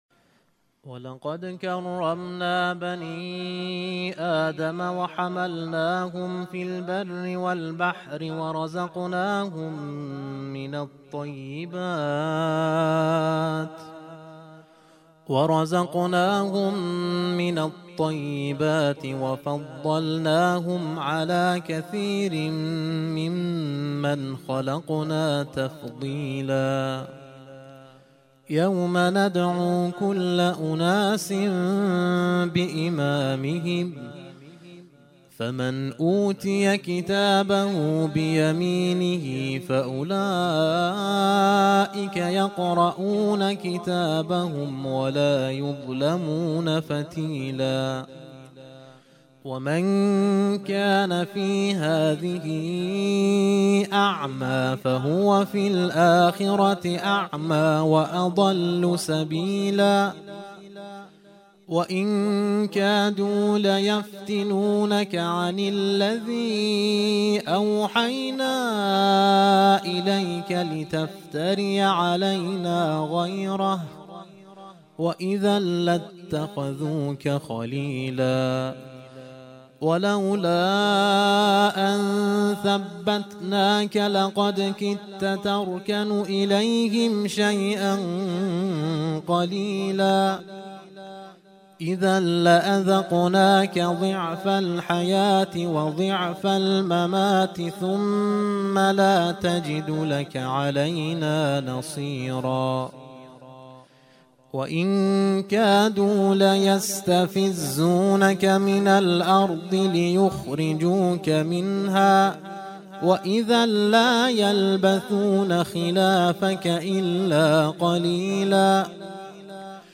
ترتیل خوانی جزء ۱۵ قرآن کریم - سال ۱۴۰۳